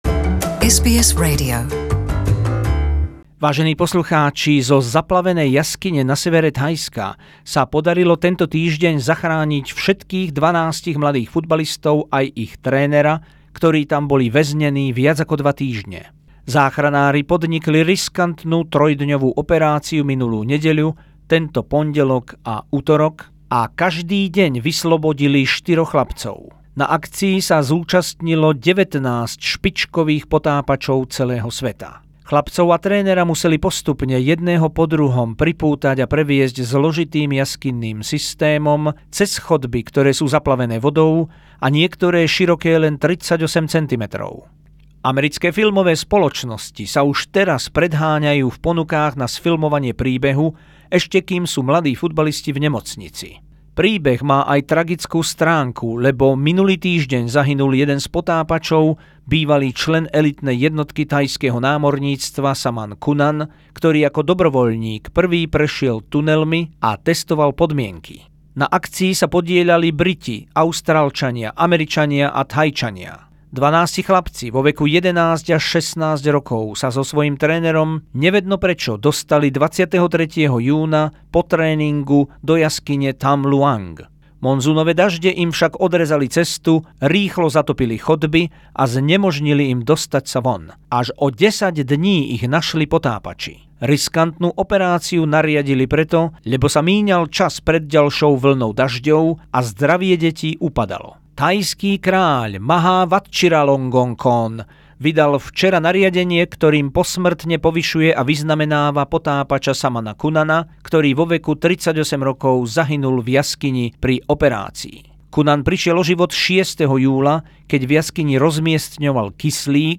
Zo spravodajskej dielne SBS.